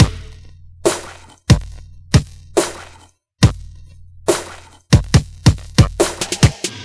Drums_B.wav